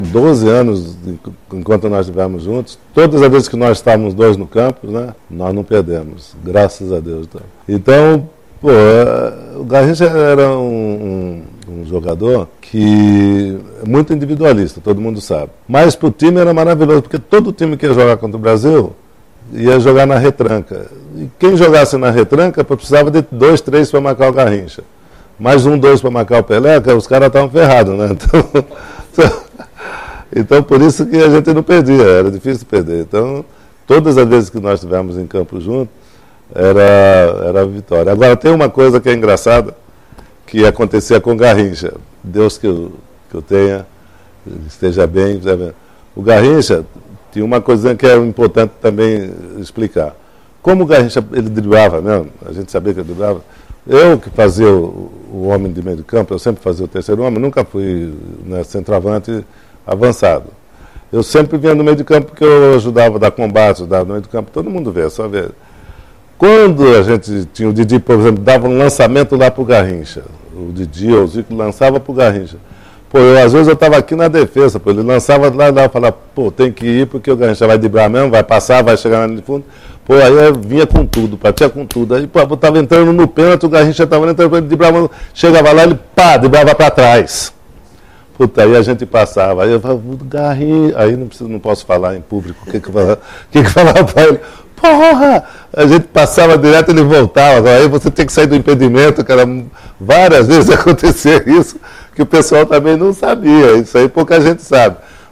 Confira a trajetória de Pelé no futebol com depoimentos e narrações históricas